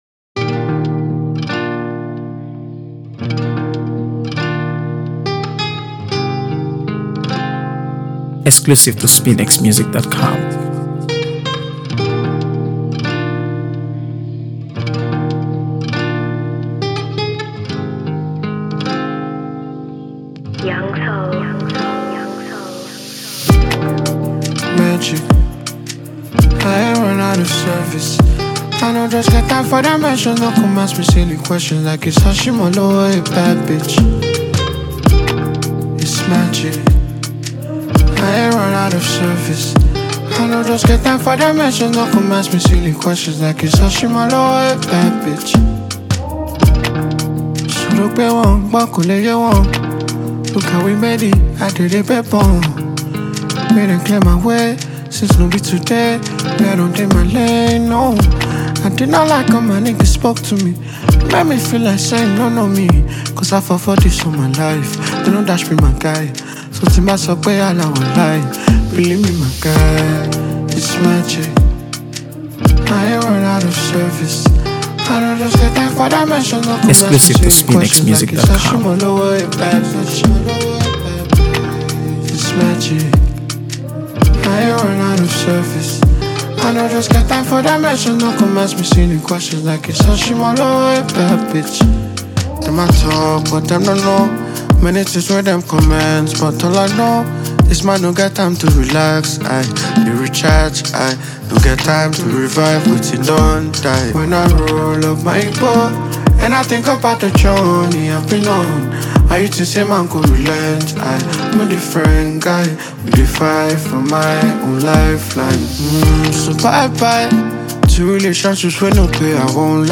AfroBeats | AfroBeats songs
modern Afrobeat rhythms
catchy hook